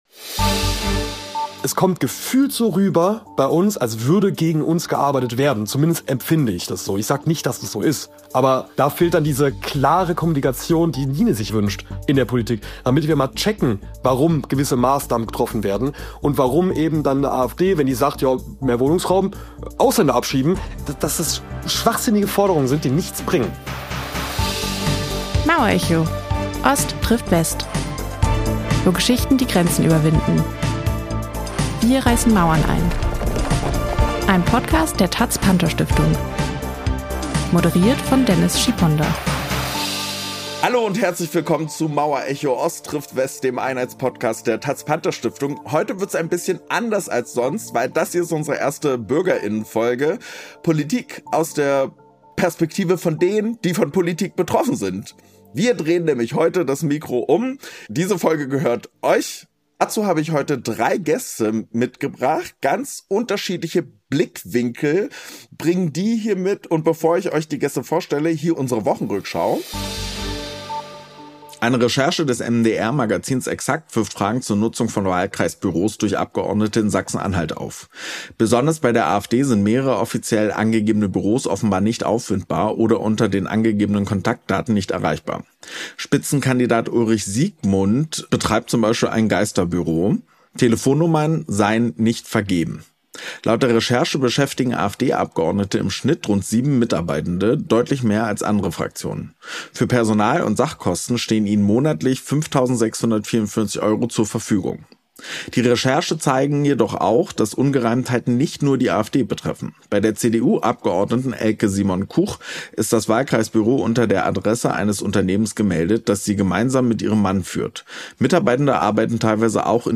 Folge 4: Bürger*innen im Gespräch ~ Mauerecho – Ost trifft West Podcast